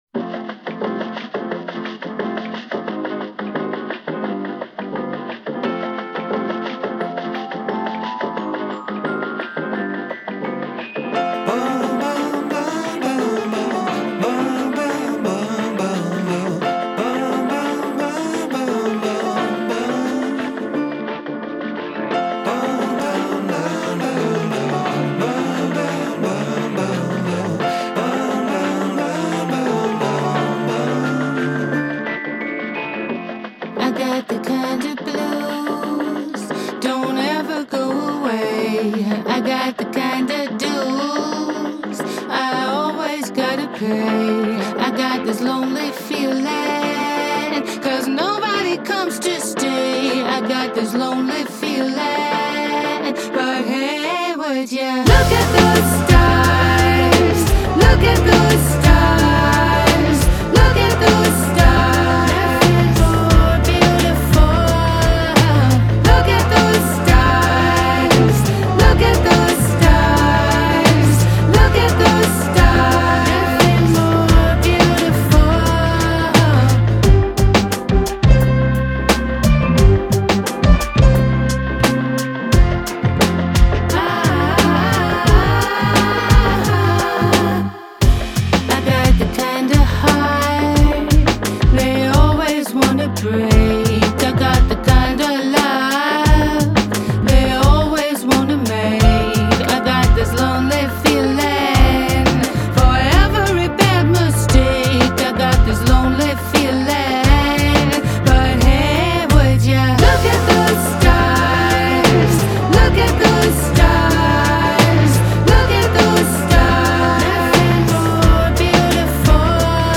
Cheerful doses of mayhem and hooks.